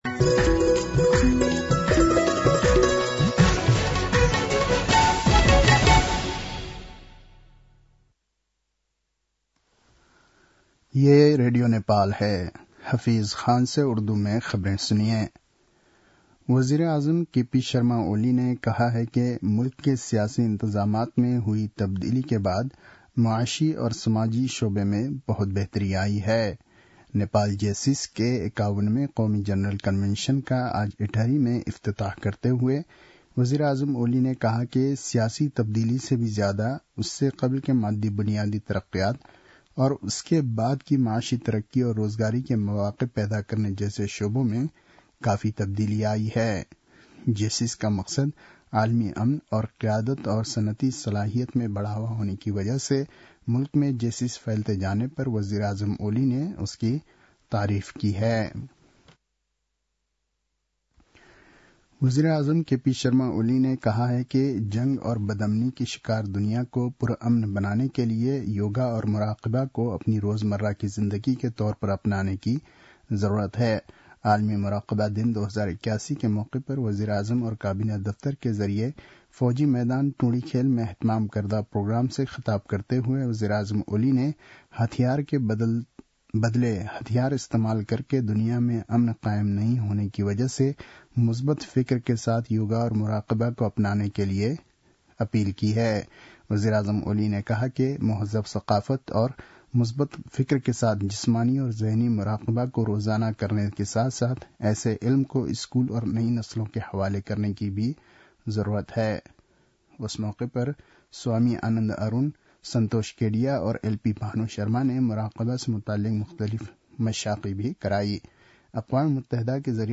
उर्दु भाषामा समाचार : ६ पुष , २०८१